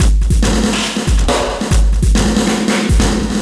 E Kit 39.wav